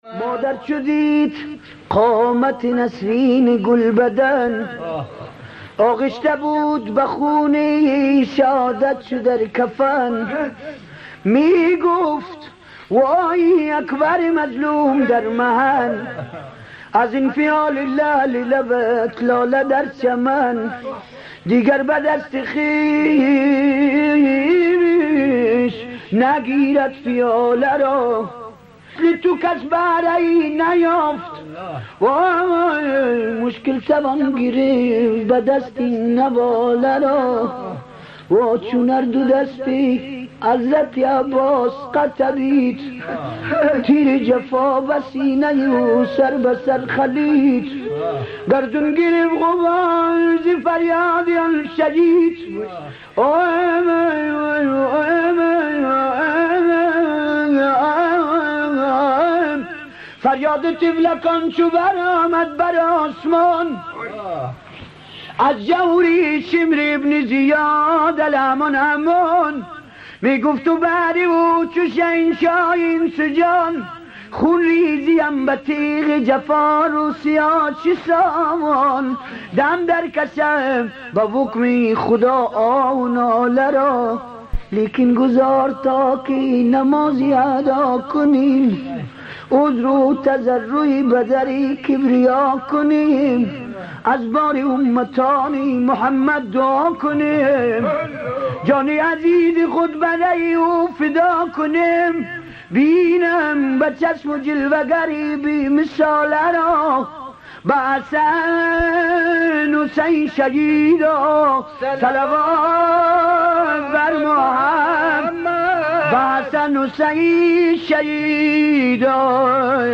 در دل شب‌های محرم، نغمه‌هایی بودند که با سوز دل مداحان قدیمی، راه دل را به کربلا باز می‌کردند.
مرثیه‌خوانی